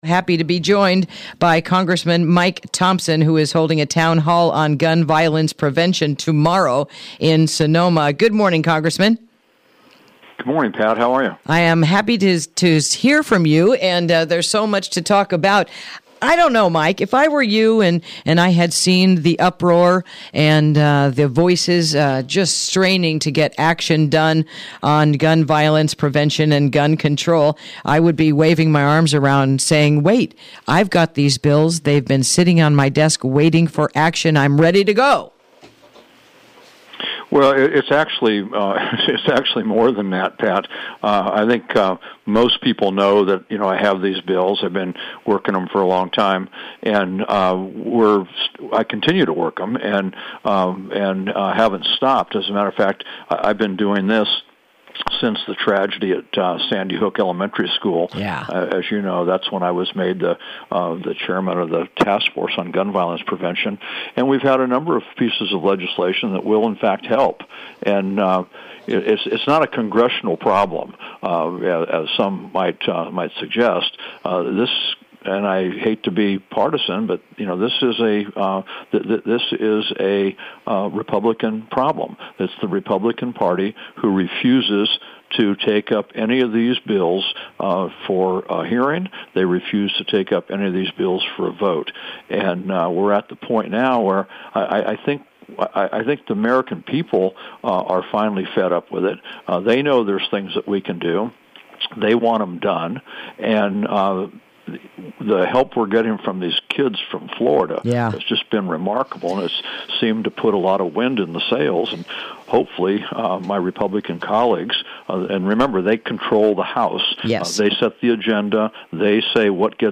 Interview: Gun Violence Prevention Town Hall on Saturday